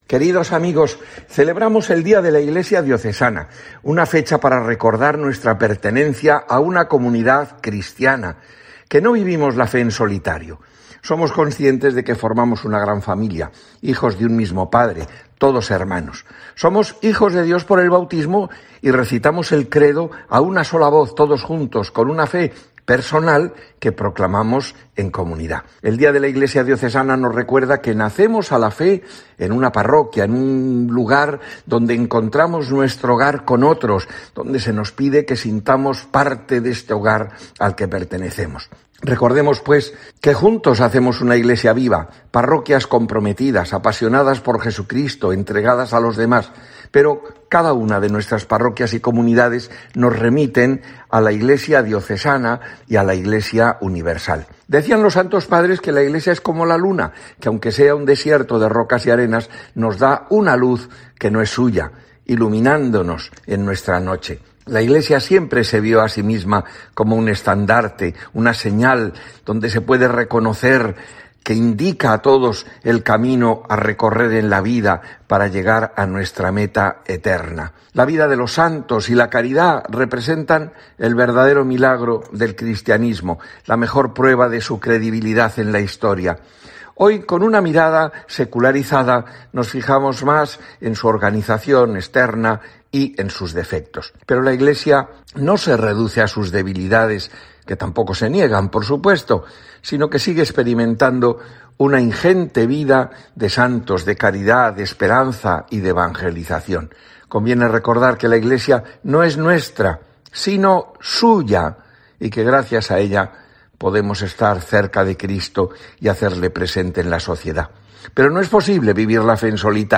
Rafael Zornoza, Obispo de la diócesis de Cádiz y Ceuta, habla del Día de la Iglesia Diocesana